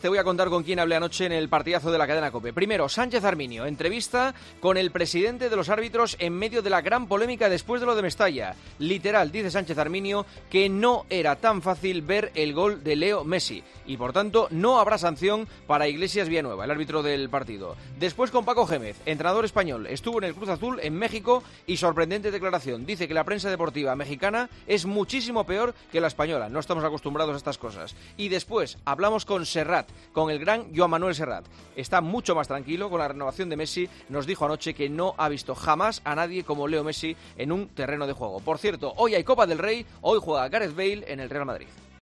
El comentario de Juanma Castaño en 'Herrera en COPE' sobre la visita del jefe del Comité Técnico de Árbitros, Victoriano Sánchez Arminio, y el gol mal anulado a Leo Messi.